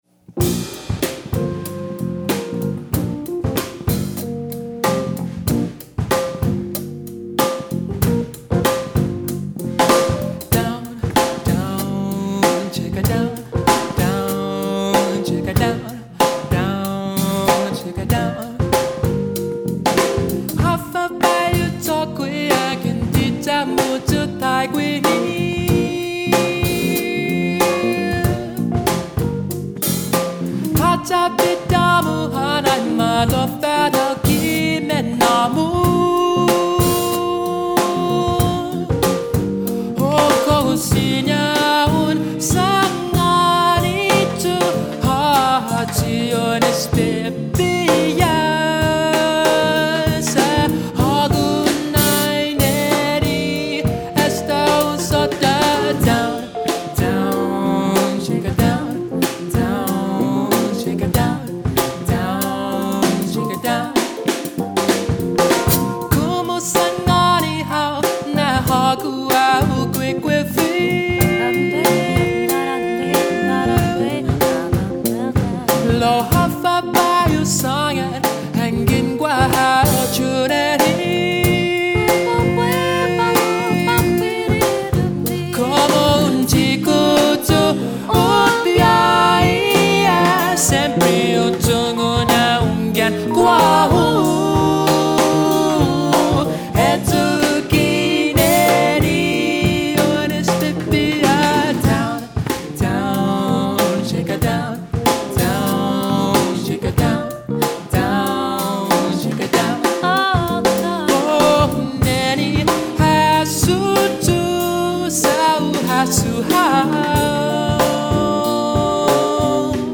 Using pop and other contemporary musical elements helps me further connect to my language and for younger generations to relate to.